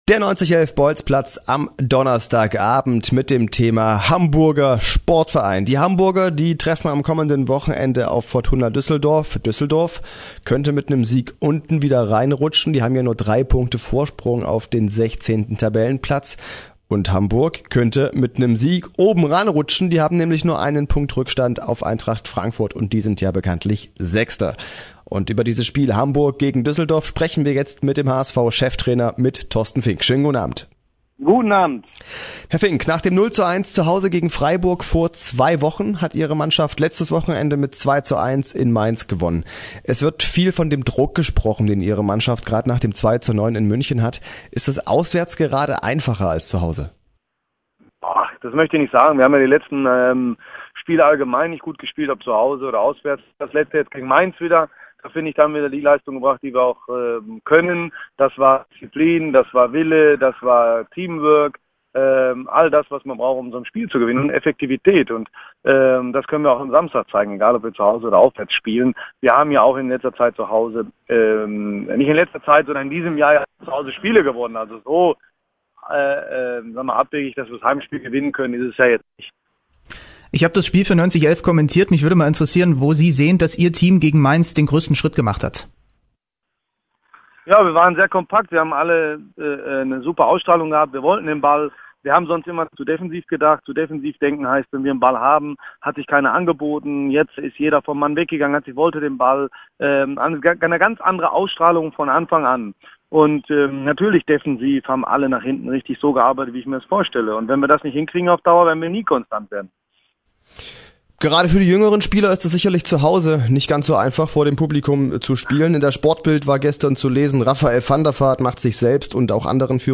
Für mich überraschend war auch Thorsten Fink ein Teil der Sendung. Hier sein Interview: